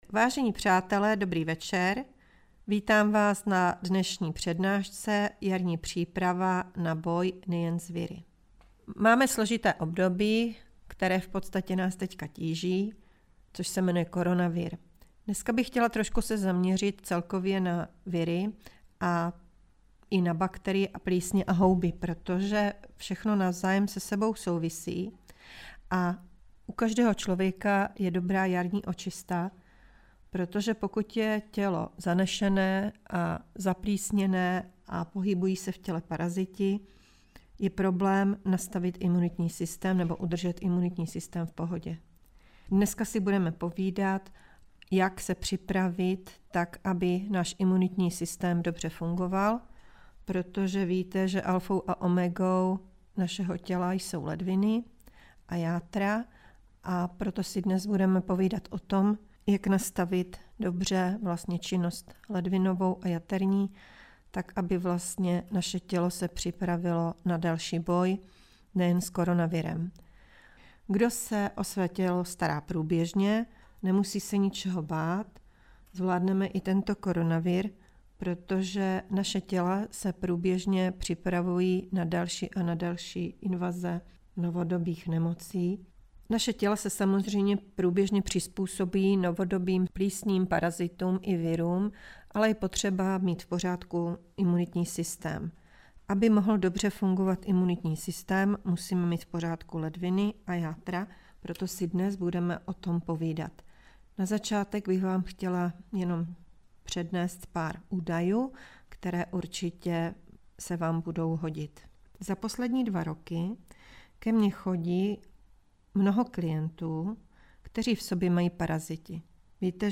Přednáška Jarní příprava na boj (nejen) s viry
Jednoduchá a účinná jarní očistná a detoxikační kůra pomocí fáze měsíce a bylin, kterou můžeme pravidelně používat na boj s různými viry a bakteriemi. A nejen to, přednáška volnou formou navazuje na kurz Hubneme zdravě a trvale, protože kdo by se nechtěl zbavit zbytečných kil a depresivní nálady.